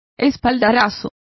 Also find out how espaldarazos is pronounced correctly.